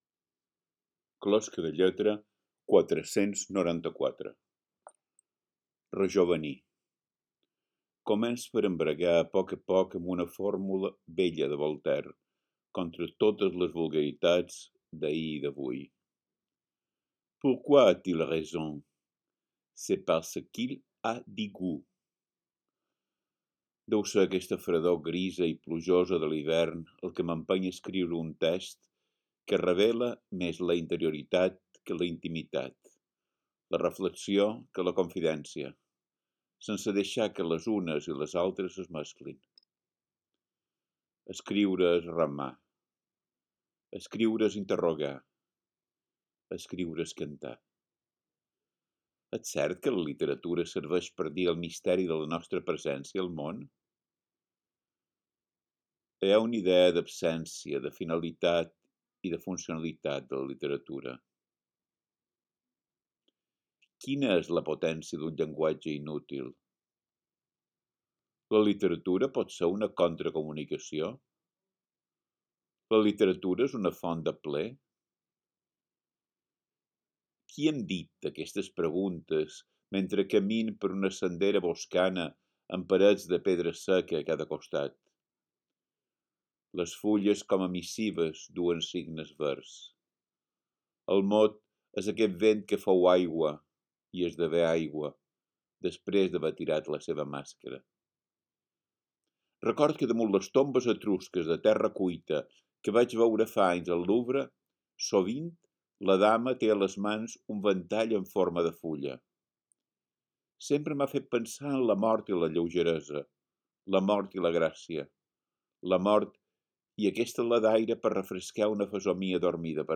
Podeu sentir el text recitat per Biel Mesquida mateix: